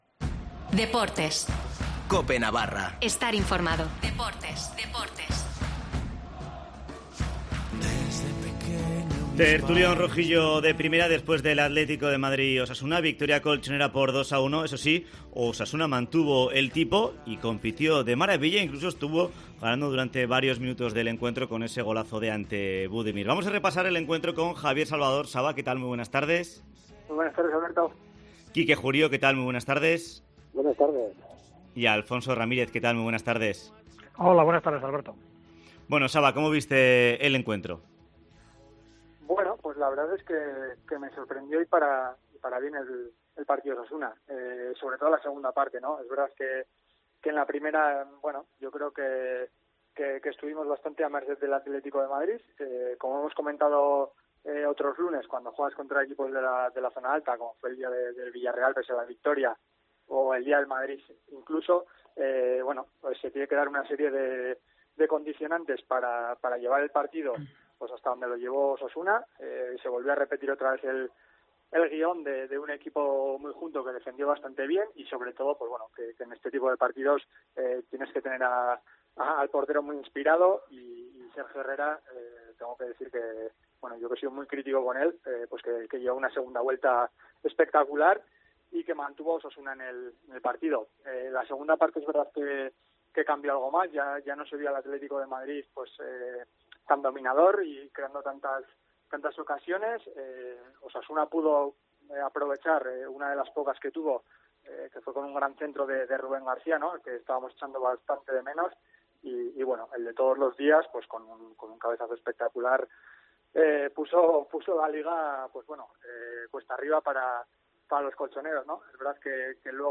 Tertulión rojillo de Primera tras el Atlético de Madrid-Osasuna